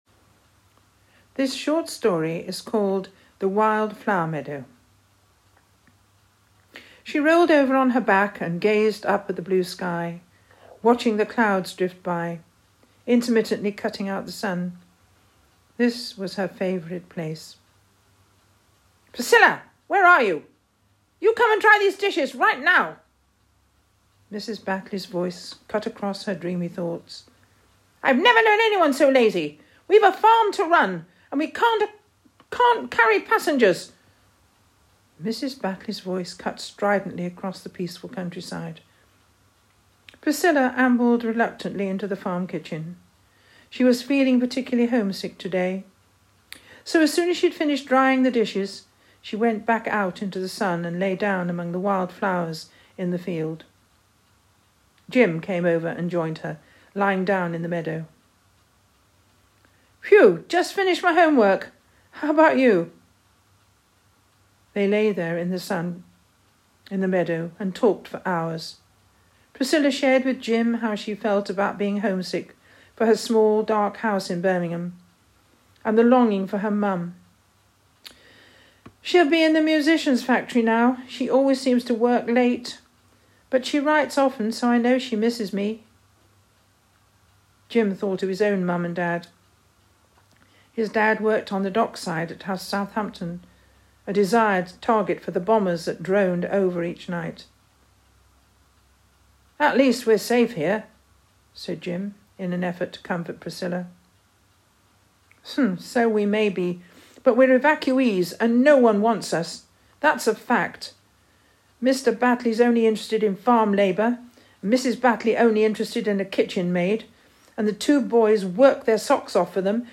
audio stories